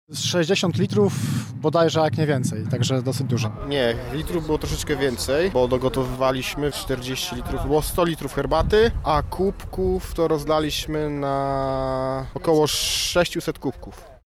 Przez Lublin przeszedł Orszak Trzech Króli – mówią dowódcy pododdziałów Polowej Drużyny Sokolej